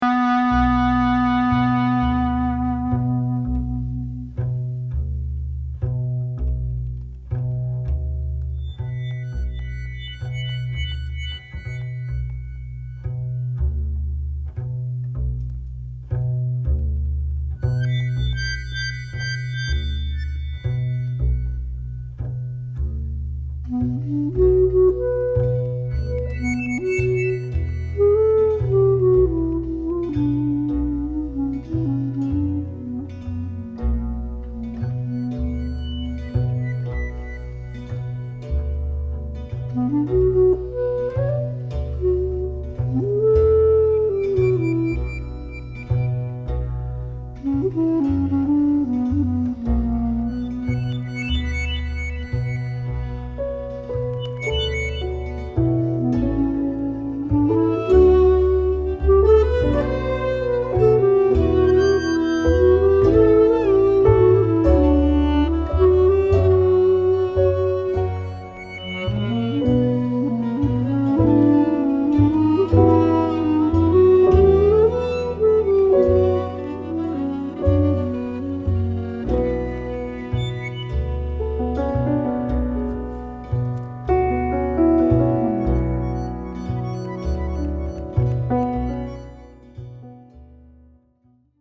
Genres: Klezmer, Jazz, World.
clarinet